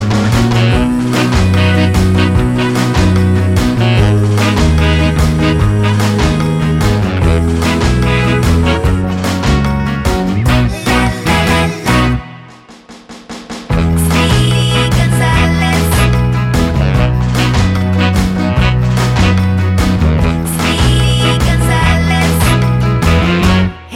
Mexican Girl Vocals Pop (1960s)